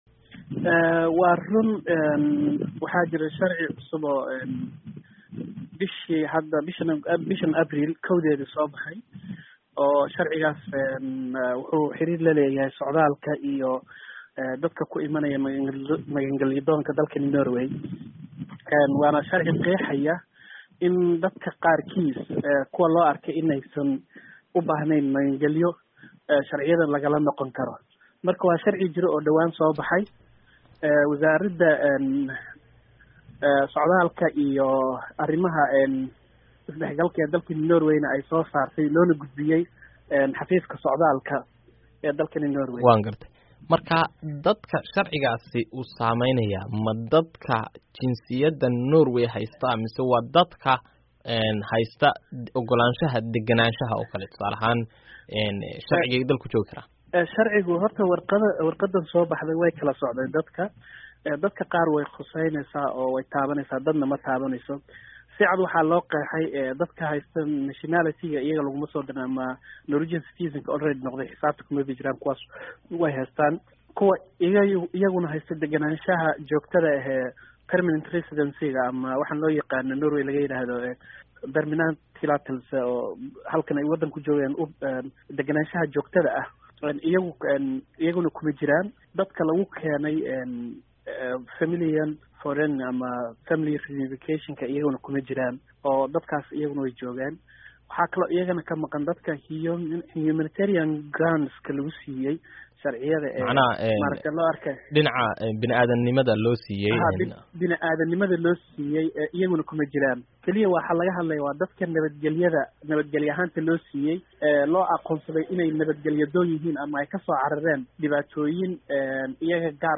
Wareysi: Soomaalida Norway